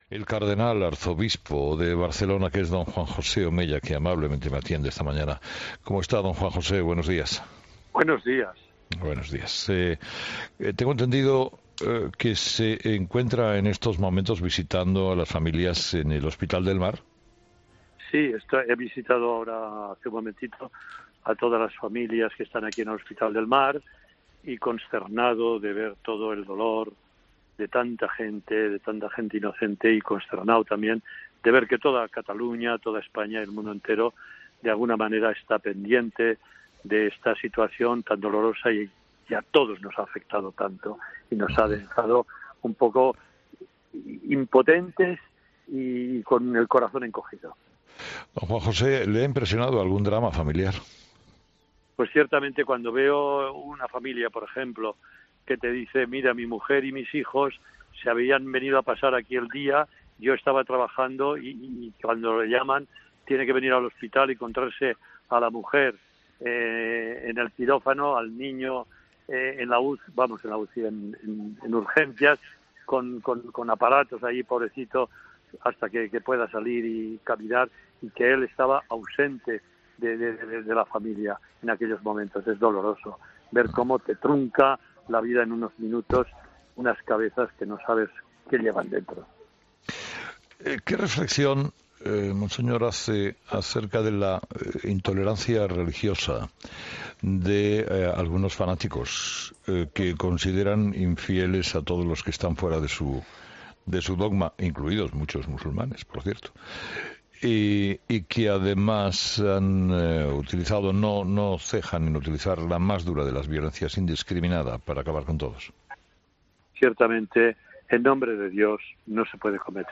ESCUCHA LA ENTREVISTA COMPLETA | Juan José Omella, Cardenal Arzobispo de Barcelona en 'Herrera en COPE'